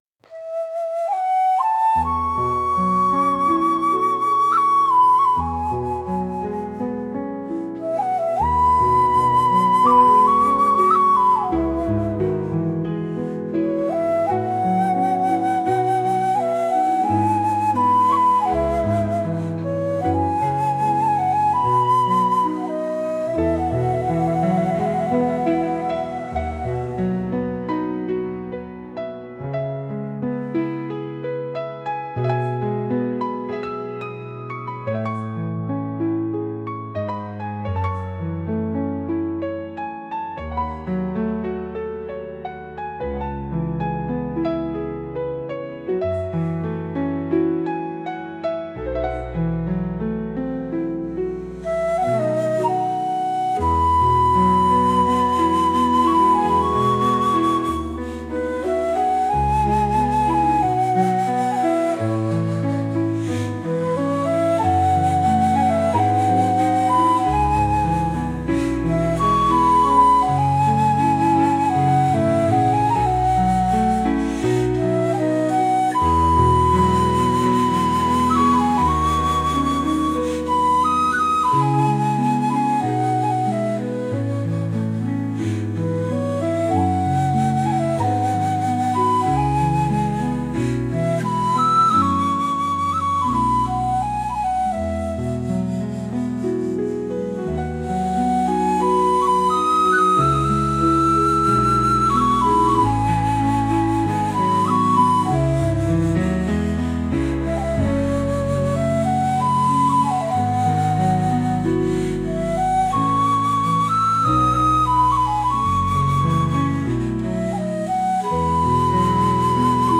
懐かしさを感じさせるような和楽器の曲です。